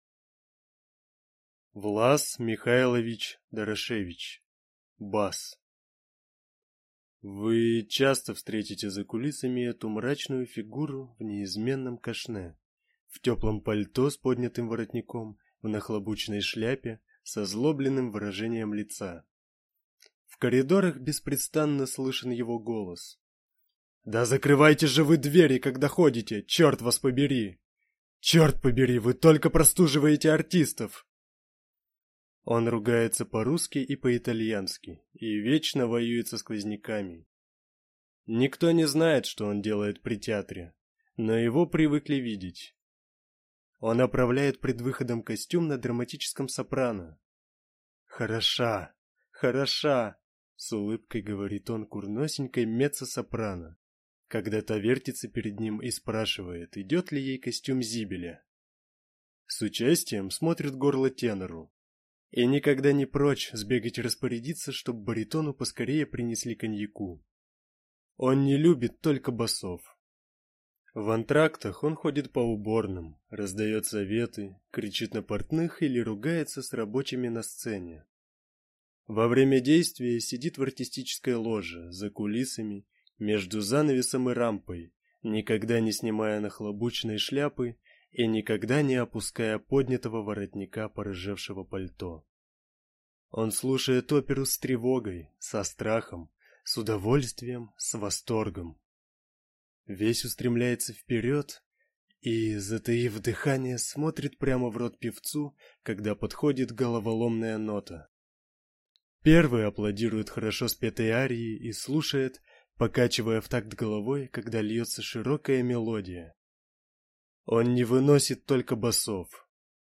Аудиокнига Бас | Библиотека аудиокниг